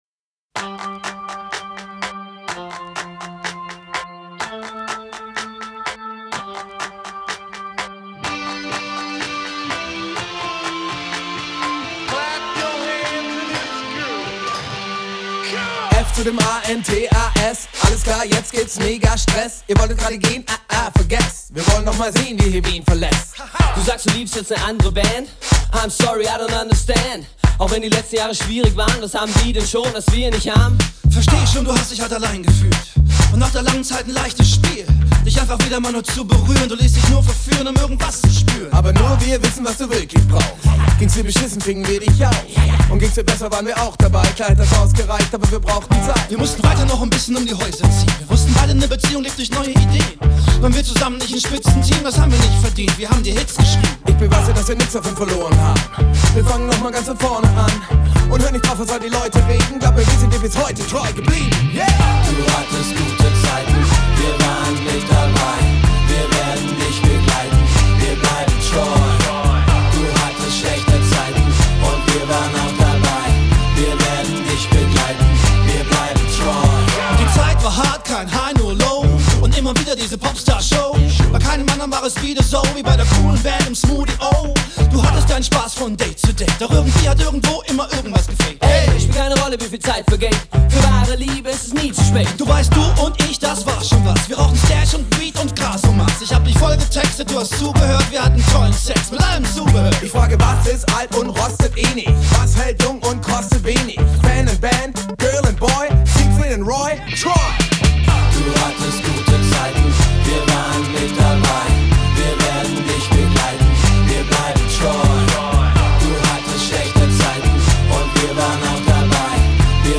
Hip Hop GER